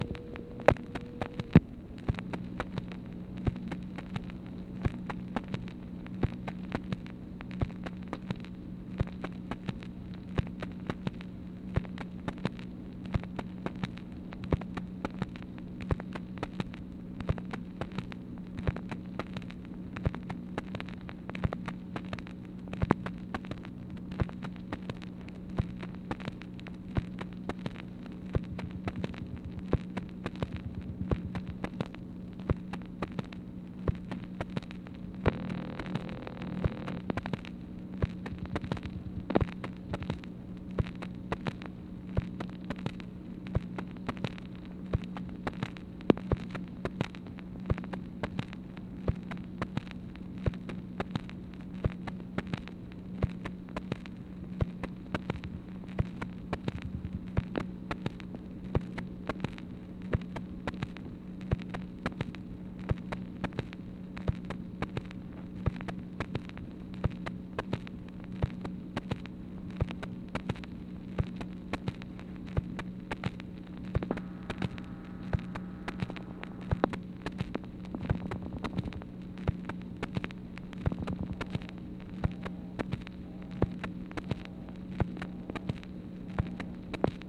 MACHINE NOISE, March 18, 1964
Secret White House Tapes | Lyndon B. Johnson Presidency